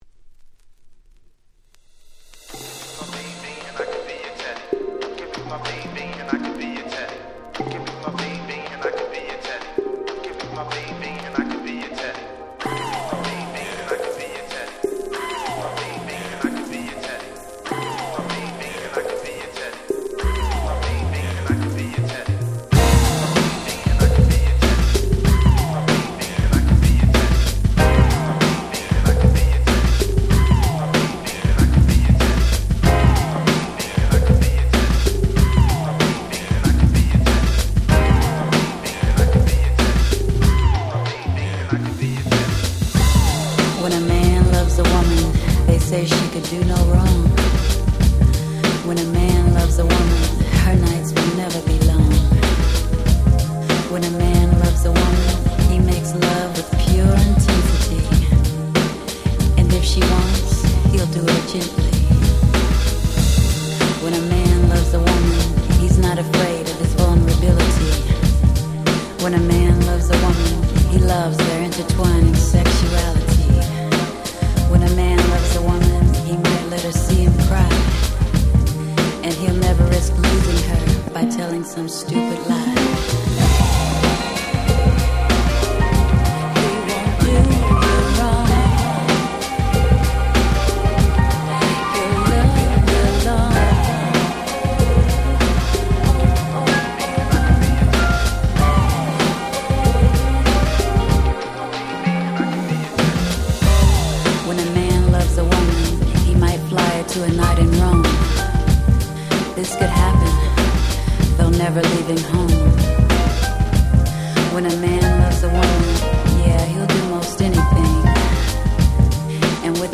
94' Smash Hit R&B !!
90's